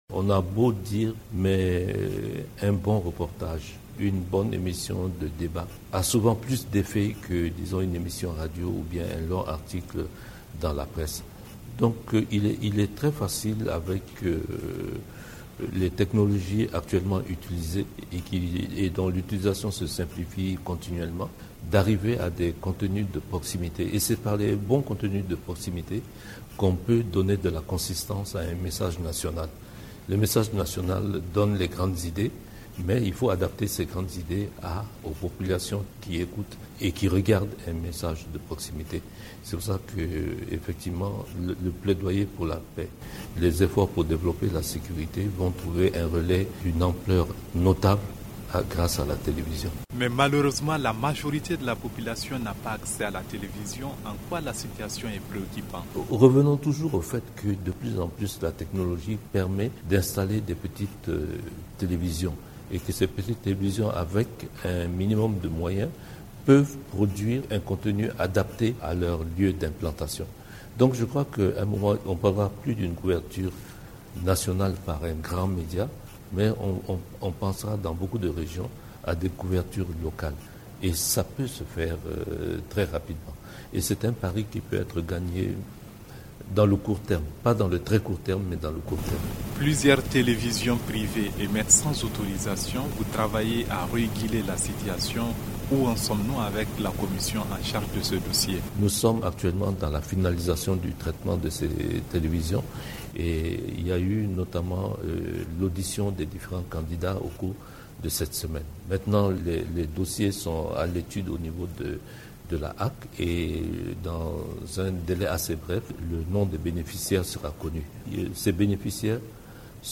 Gaoussou Drabo, ancien ministre de la communication est au micro